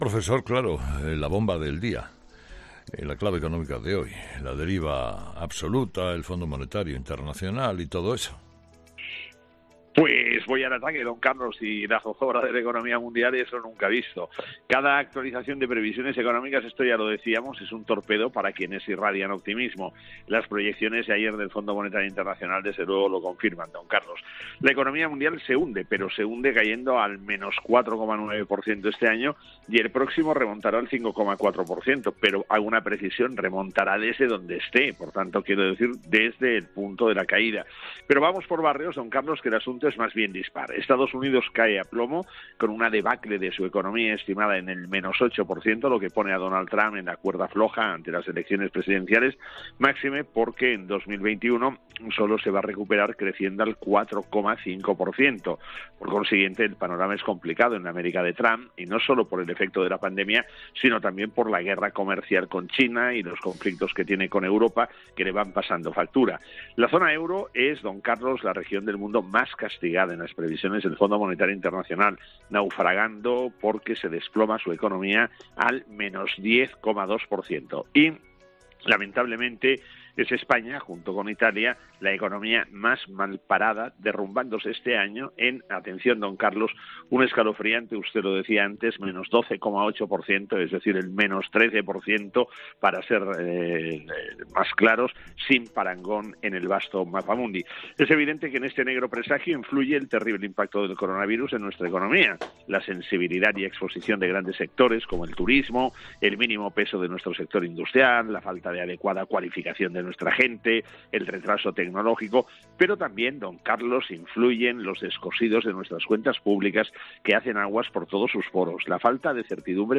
El profesor José María Gay de Liébana analiza en ‘Herrera en COPE’ las claves económicas del día